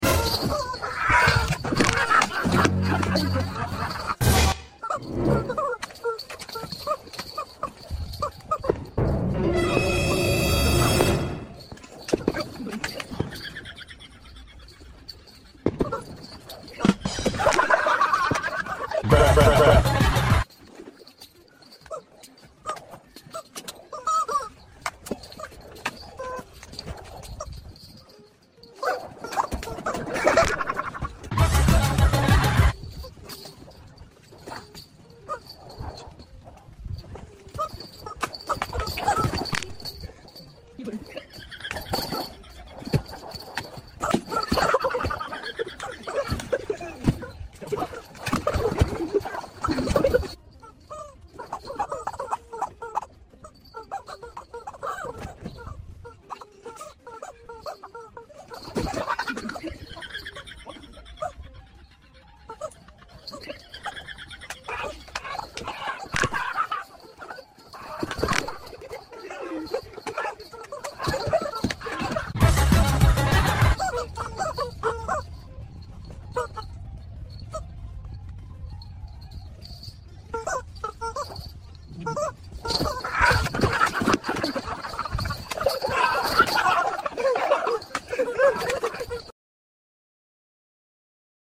Screaming Chicken Blindfolded and Beating